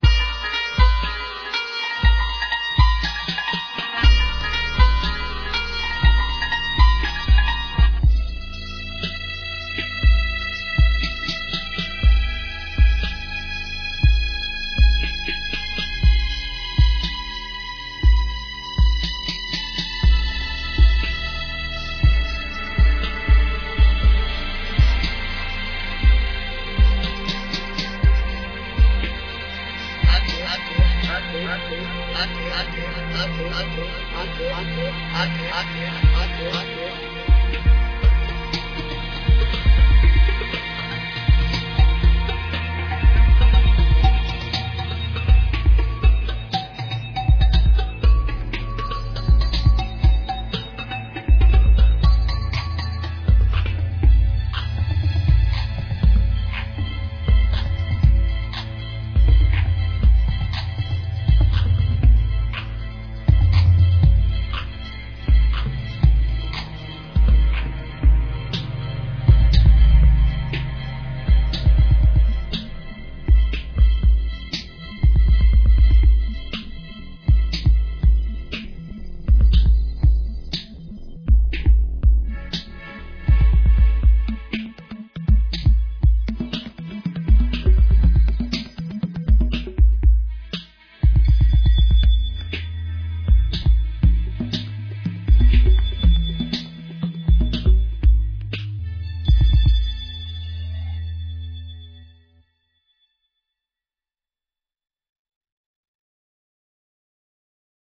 Hip hop beats. Rhythm. Percussion. Sweet.